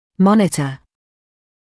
[‘mɔnɪtə][‘монитэ]наблюдать; отслеживать